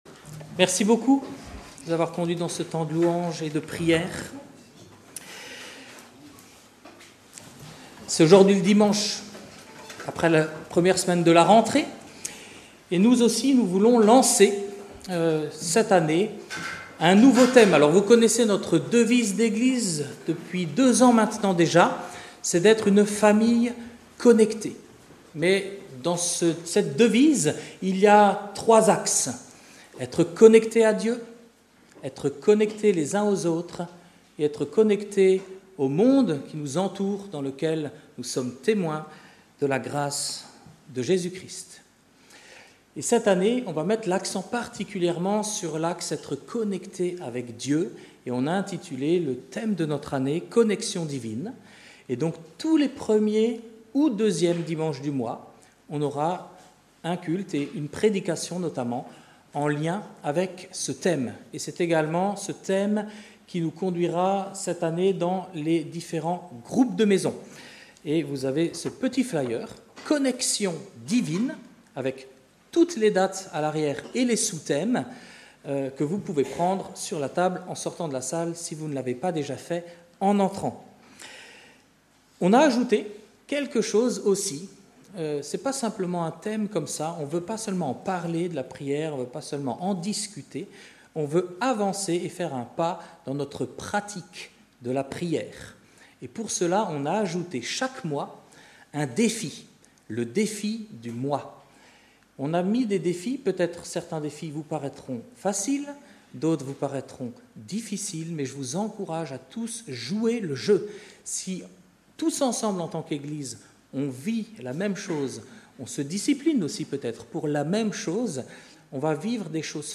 Culte du dimanche 7 septembre 2025 – Église de La Bonne Nouvelle